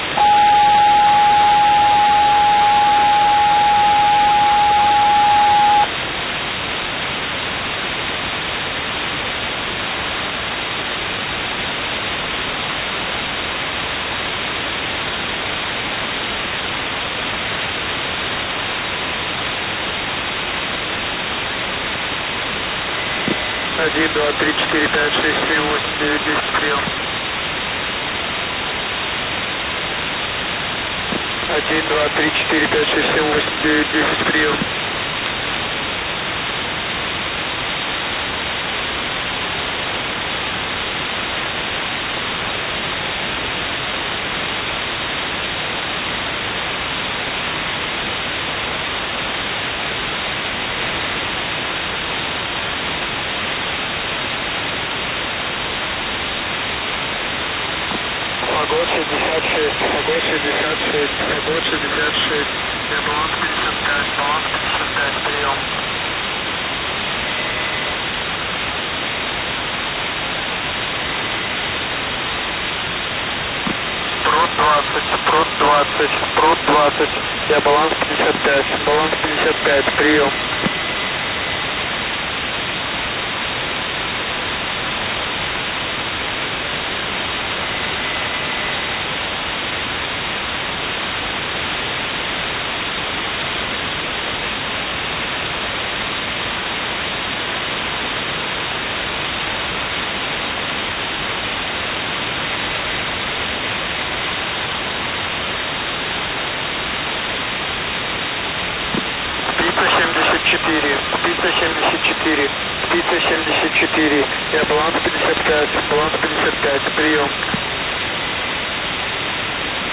• 4213.5 kHz
• J3E (USB)